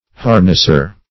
Harnesser \Har"ness*er\ (-[~e]r), n. One who harnesses.